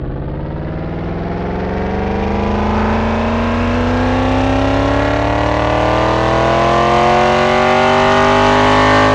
rr3-assets/files/.depot/audio/Vehicles/v8_08/v8_08_Accel.wav
v8_08_Accel.wav